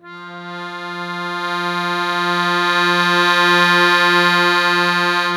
MUSETTE 1 SW.wav